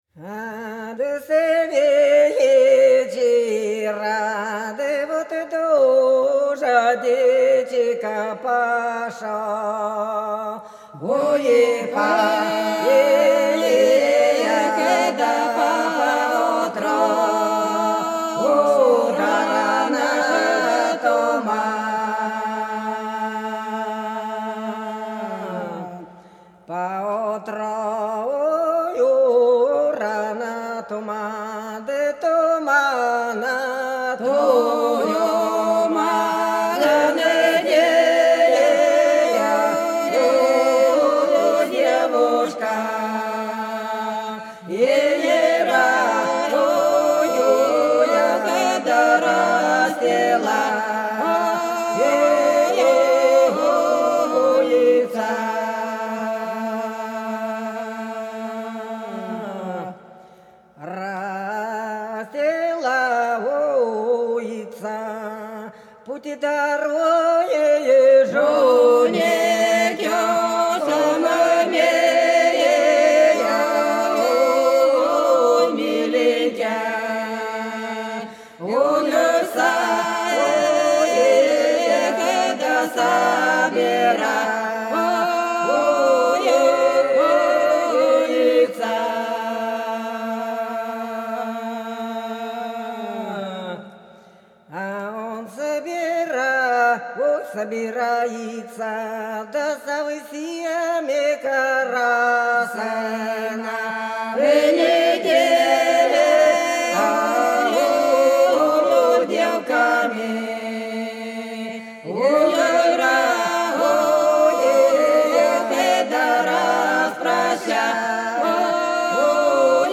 | diskname = Белгородские поля (Поют народные исполнители села Прудки Красногвардейского района Белгородской области)
| filedescription = Да вот с вечера дождичек пошел - протяжная